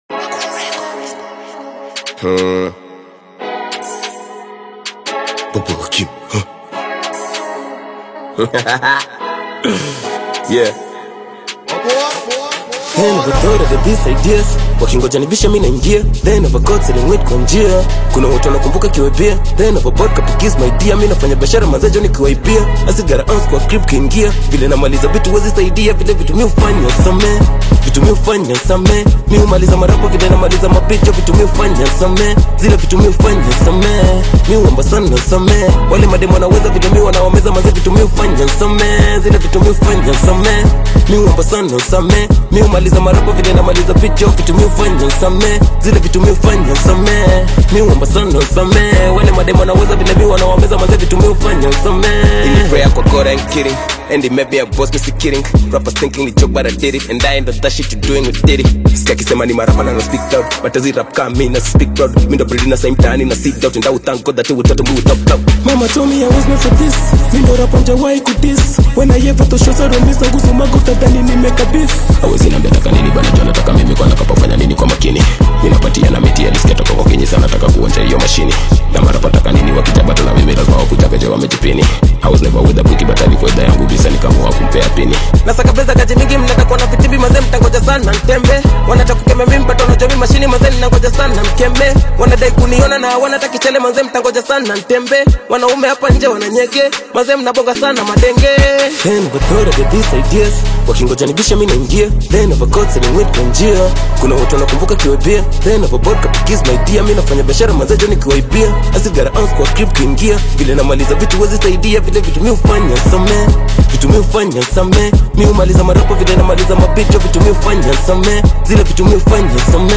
AudioHip HopKenyan Music
Hip-Hop/Rap track